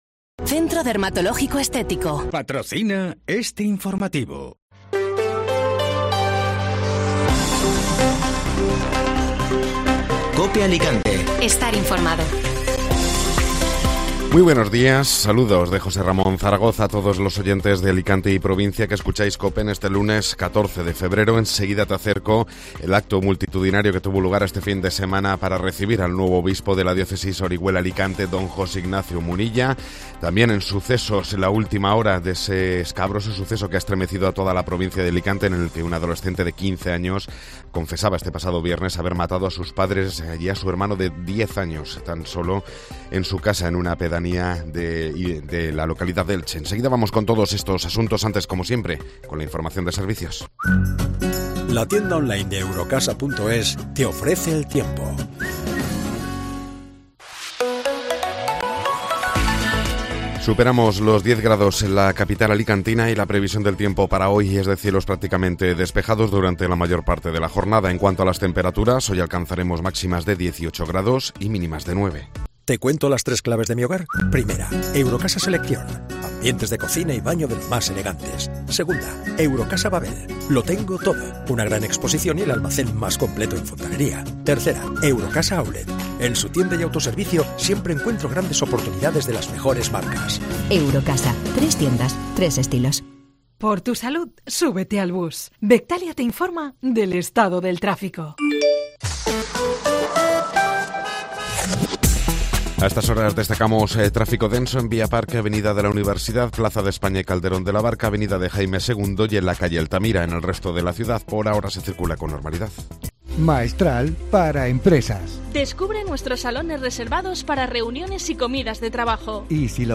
Informativo Matinal (Lunes 14 de Febrero)